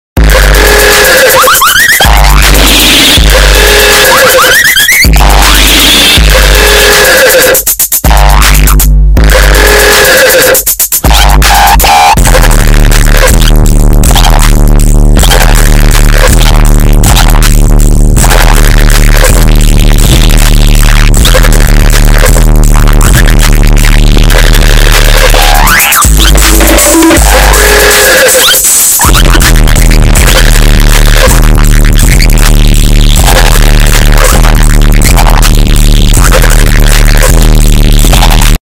Nerd Emoji Earrape Sound Effect Free Download